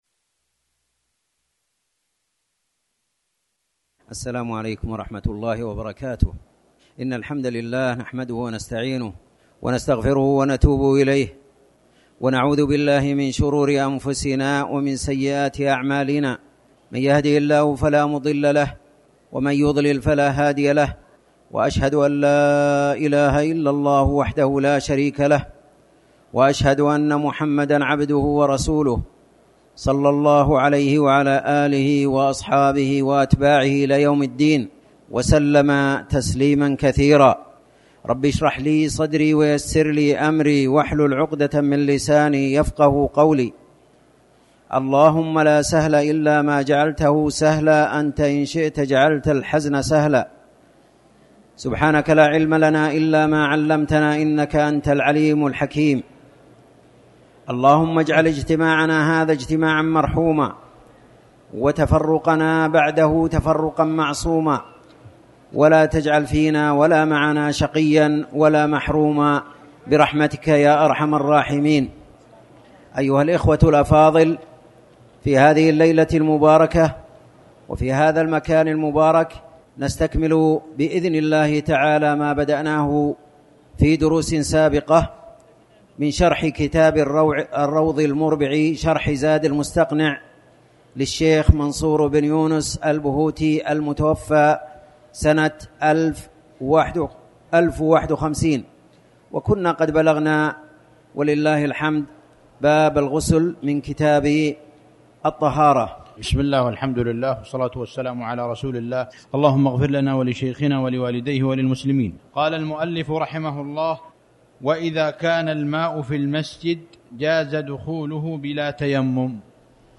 تاريخ النشر ٢٥ ربيع الأول ١٤٤٠ هـ المكان: المسجد الحرام الشيخ